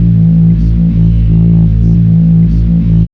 2508L B-LOOP.wav